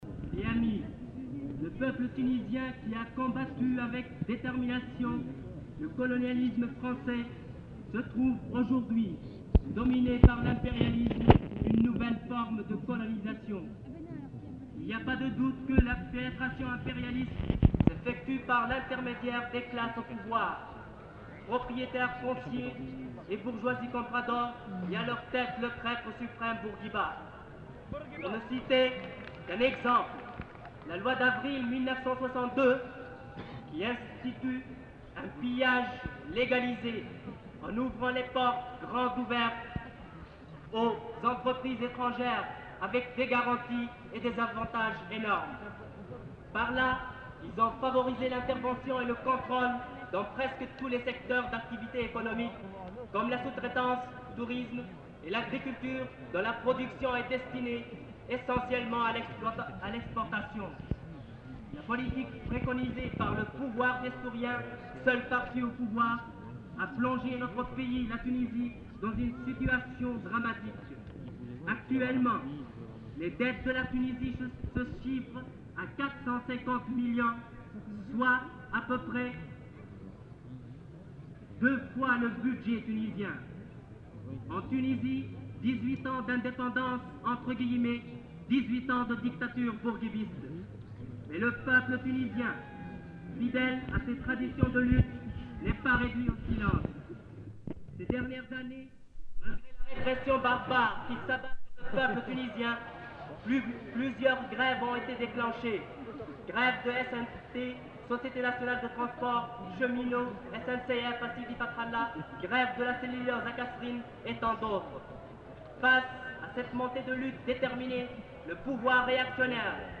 Lieu : [sans lieu] ; Aveyron
Genre : parole